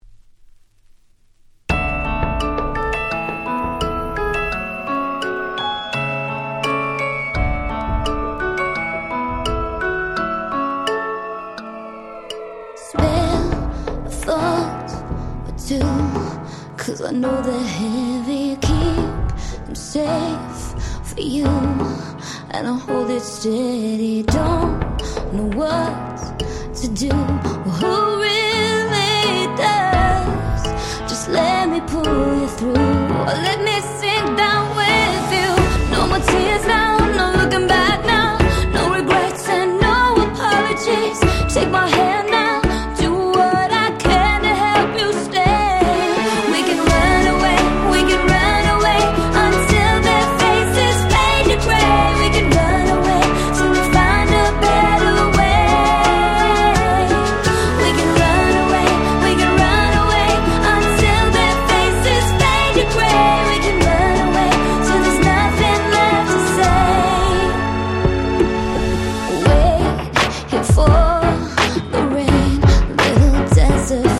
08' Nice EU R&B !!
キャッチー系